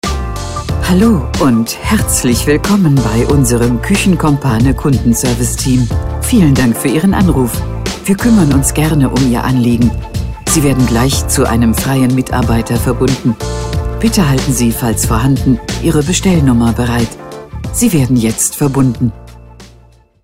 Telefonansage Küchenstudio
Ansage 1